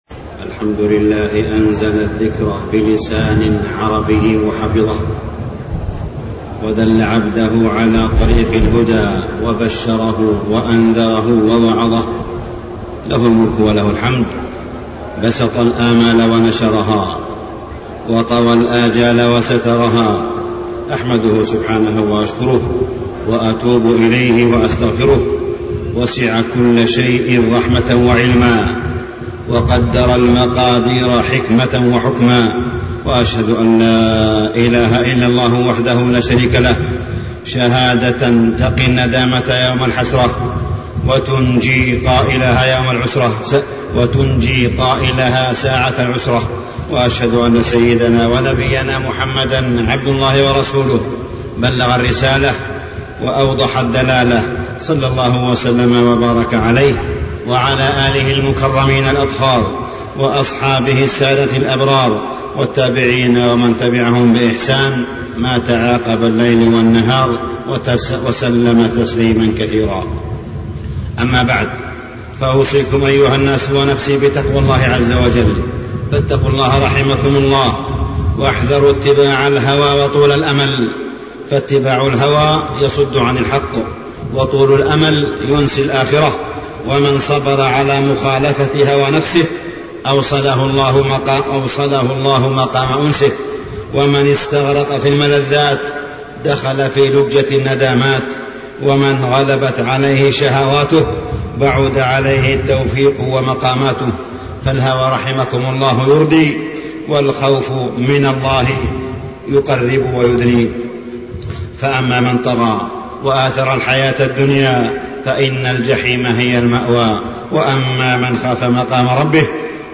تاريخ النشر ٢٢ رجب ١٤٢٩ هـ المكان: المسجد الحرام الشيخ: معالي الشيخ أ.د. صالح بن عبدالله بن حميد معالي الشيخ أ.د. صالح بن عبدالله بن حميد اللغة العربية